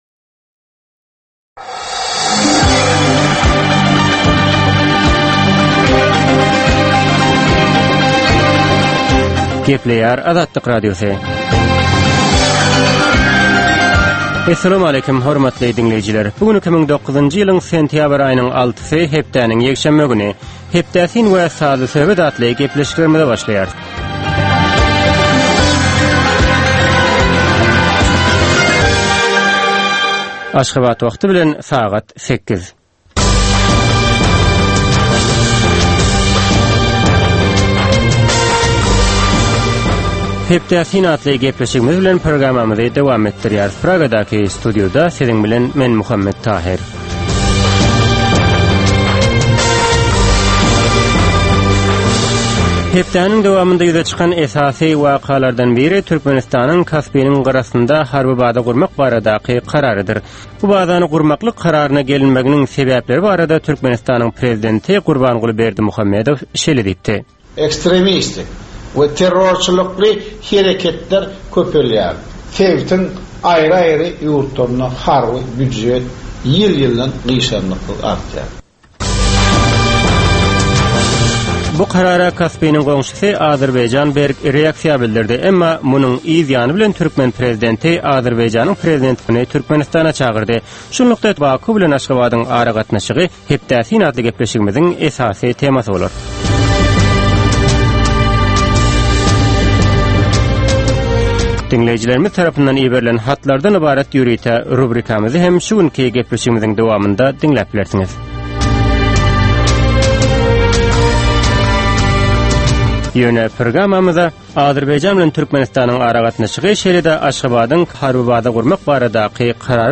Tutus geçen bir hepdänin dowamynda Türkmenistanda we halkara arenasynda bolup geçen möhüm wakalara syn. 30 minutlyk bu ýörite programmanyn dowamynda hepdänin möhüm wakalary barada gysga synlar, analizler, makalalar, reportažlar, söhbetdeslikler we kommentariýalar berilýar.